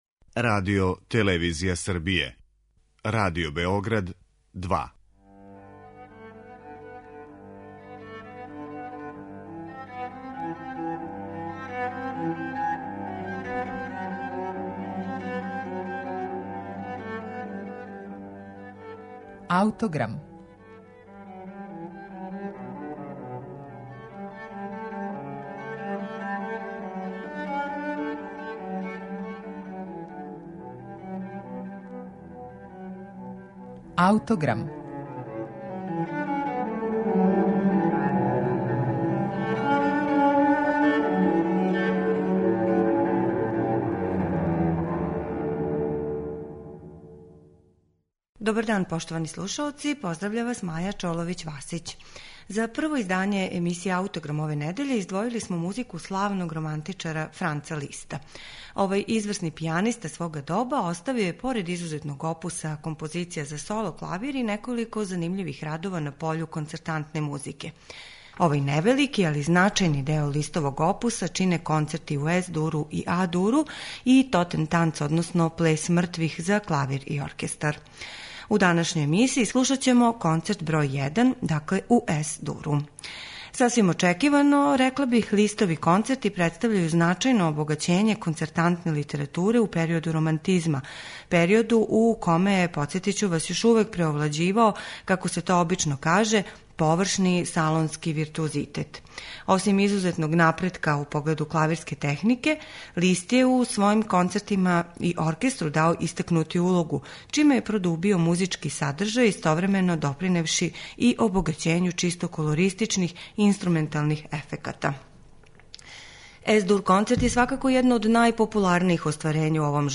Франц Лист ‒ Концерт за клавир и оркестар у Ес-дуру
Овај концерт се сврстава у највеће изазове за савремене пијанисте, а једну од најупечатљивијих интерпретација остварила је Марта Аргерич уз Лондонски симфонијски оркестар, под управом Клаудија Абада.